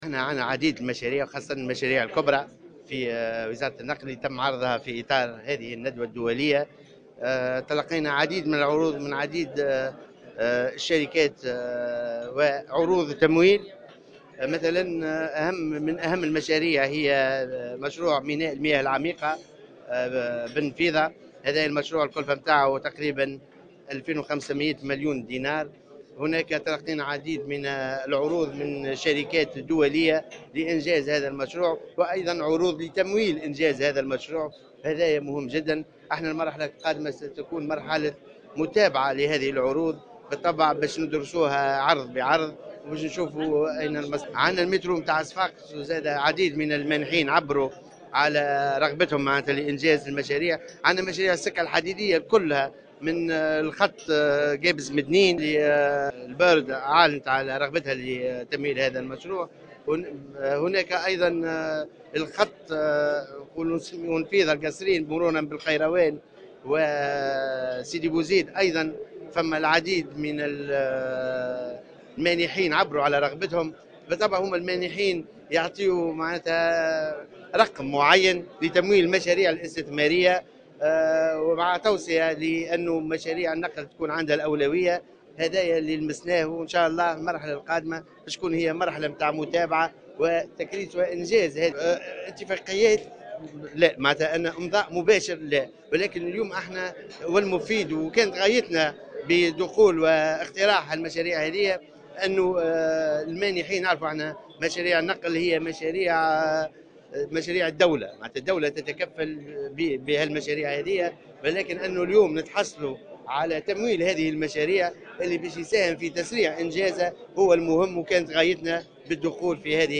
أكد وزير النقل أنيس في تصريح للجوهرة "اف ام" اليوم الأربعاء 30 نوفمبر 2016 تلقيهم عدة عروض خلال اليوم الأول من مؤتمر الإستثمار من شركات دولية لإنجاز وتمويل عدة مشاريع تم طرحها أمس .